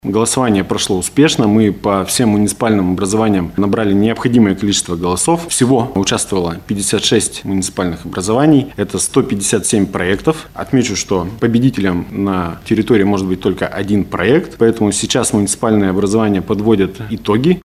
Также регион стал лидером по количеству волонтеров, задействованных в проведении голосования. Об этом рассказал заместитель министра энергетики и ЖКХ Свердловской области Василий Фадеев на пресс-конференции «ТАСС-Урал».